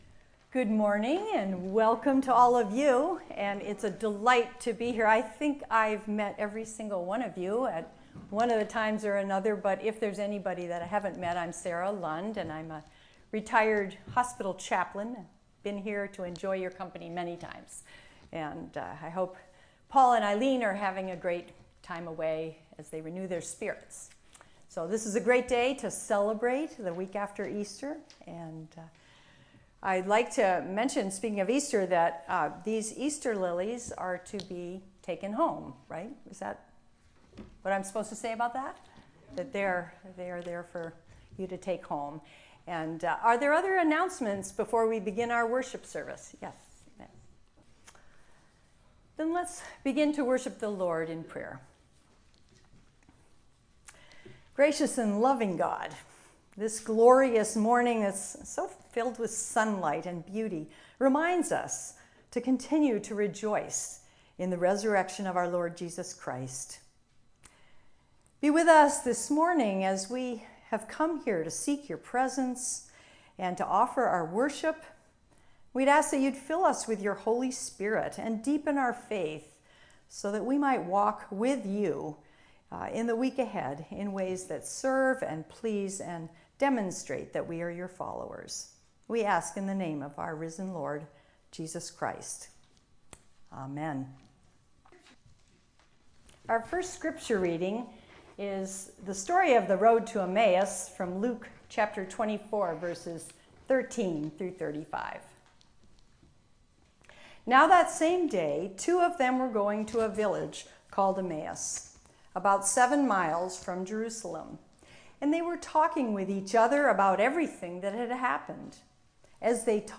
Guest Minister
sermon.mp3